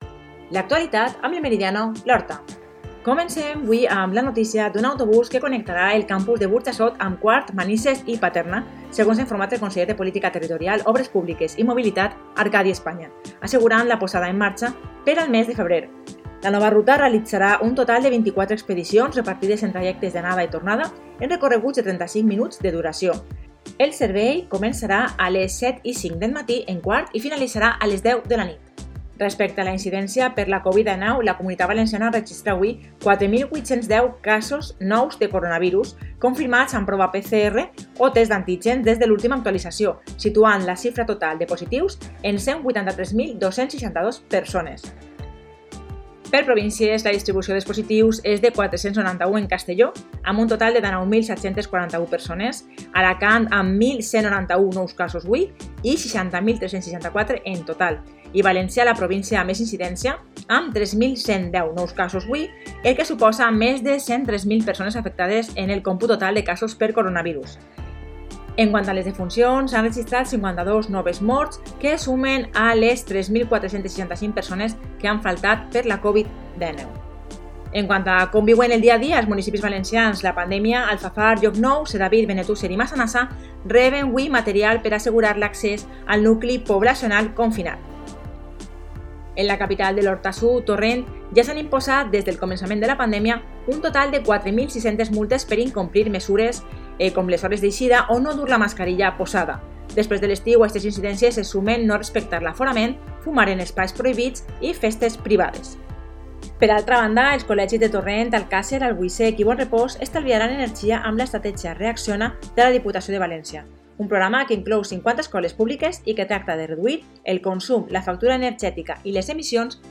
Informativo: 13-1-21: